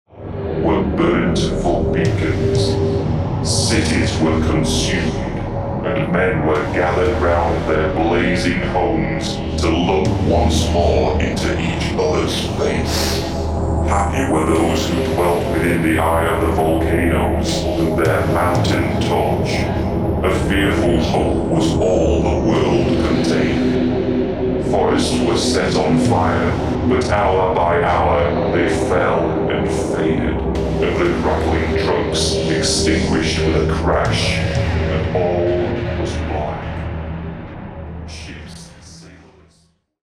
Satanischer Death Industrial aus Mexico.